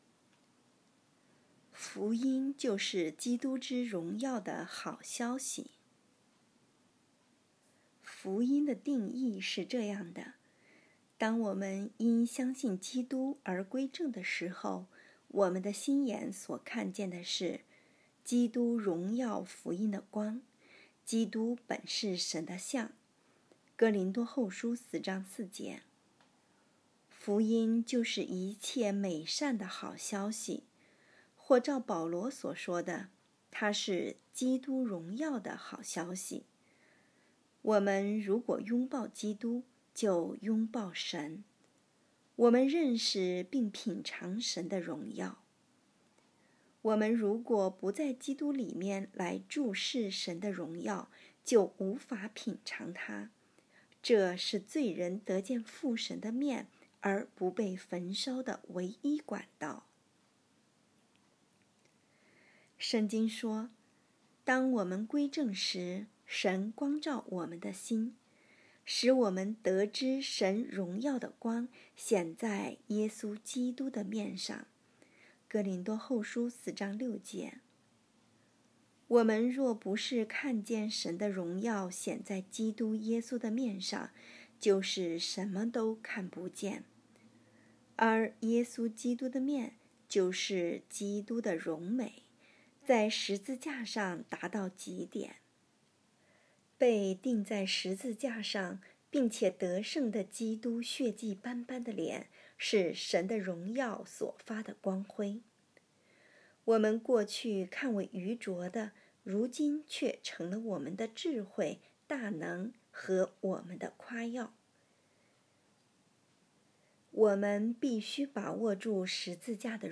2023年8月2日 “伴你读书”，正在为您朗读：《活出热情》 https